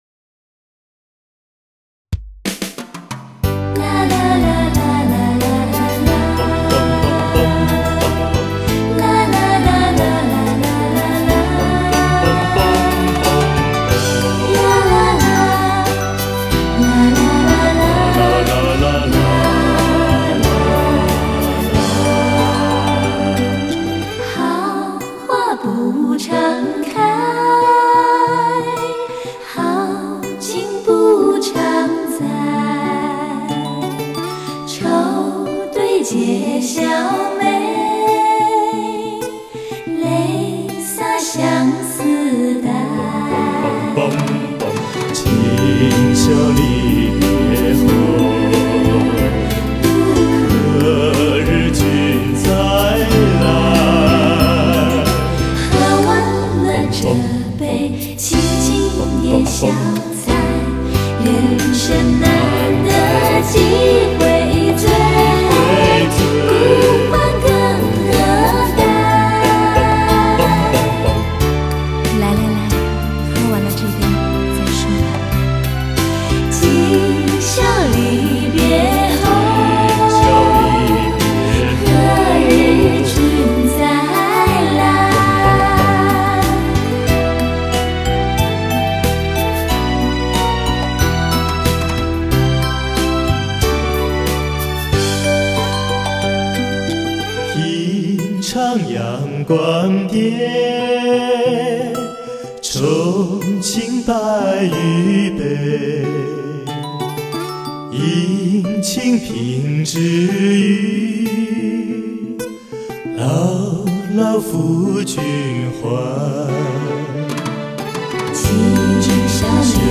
錄音機：TASCAMA-80 24軌糢擬錄音機 MIC:U-87 非常廣泛使用的一種電容麥尅風